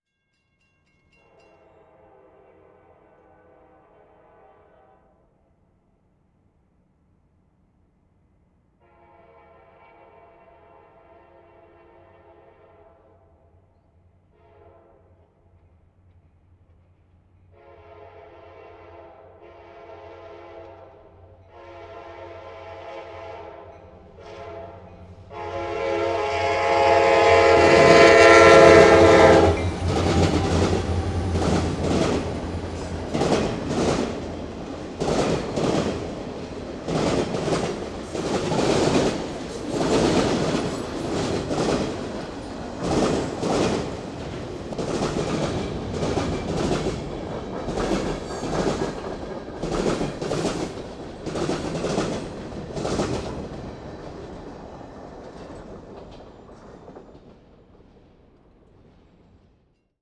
Tetrahedral Ambisonic Microphone
Recorded January 21, 2010, at the crossing of the Union Pacific and Austin and Western railroads, McNeil, Texas